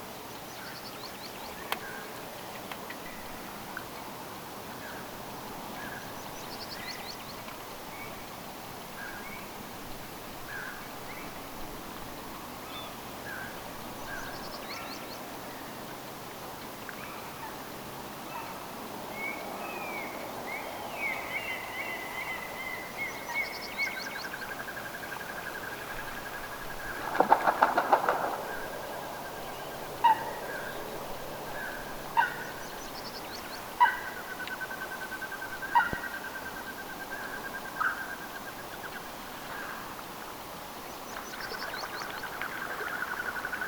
tuollaista laulua se mahdollisesti
itäisempi pajusirkku nyt laulaa
Tämän laulun loppuhuipennus tuo mieleen tiaisen??
tuollaista_laulua_vetaa_nyt_se_mahd_itaisempi_pajusirkkulintu.mp3